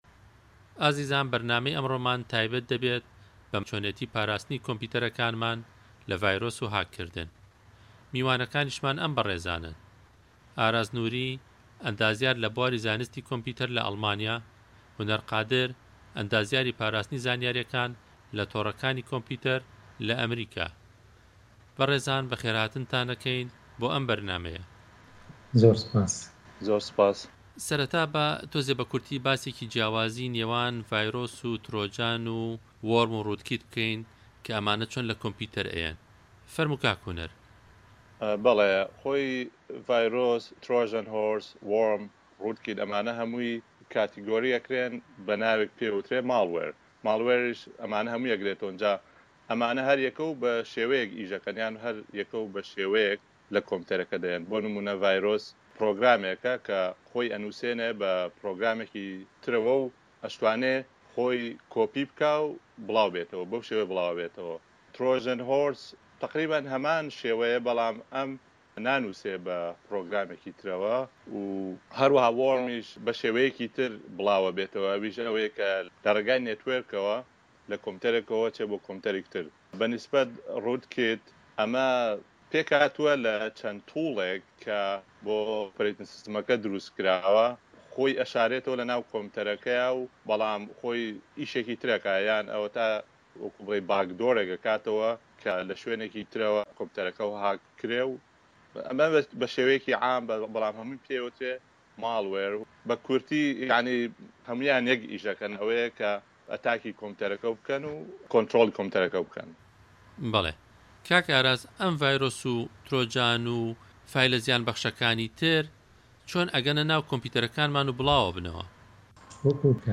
ده‌قی مێزگرده‌که‌